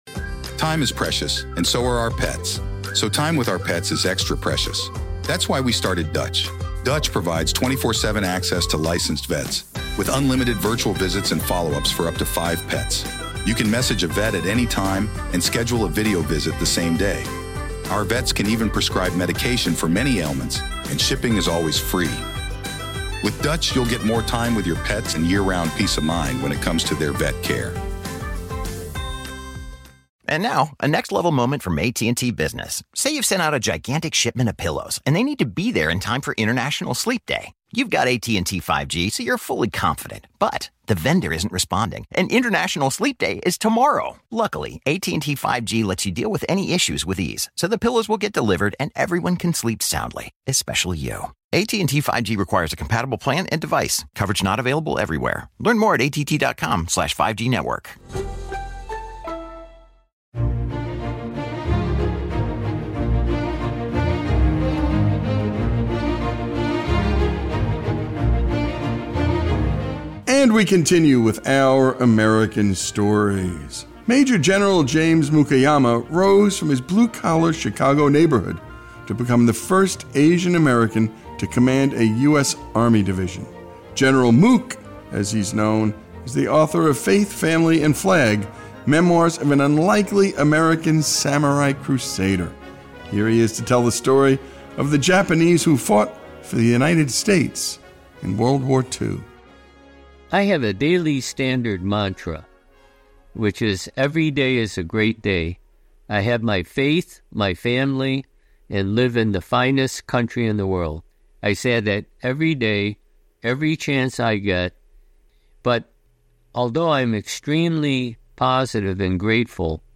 World War II Asian American Veterans Military History Japanese American American Samurai discussion